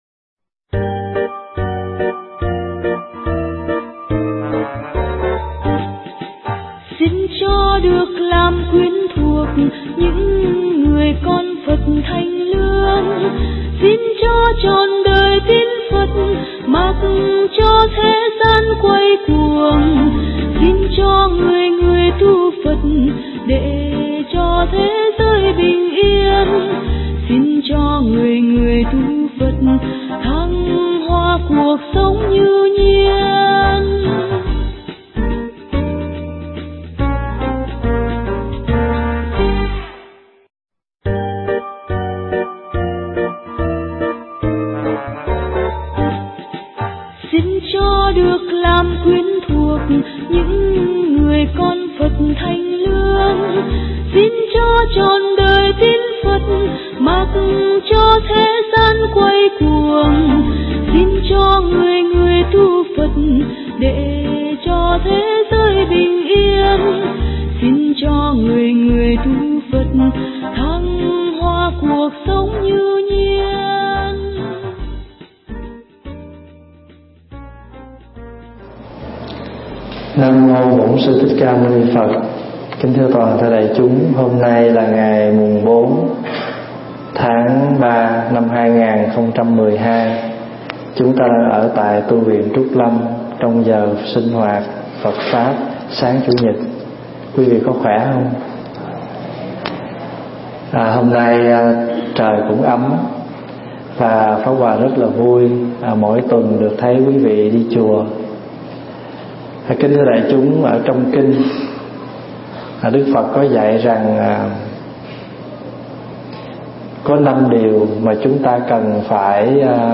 Mp3 Thuyết Giảng Tu Mau Kẻo Trễ
thuyết giảng tại Tu Viện Trúc Lâm, Canada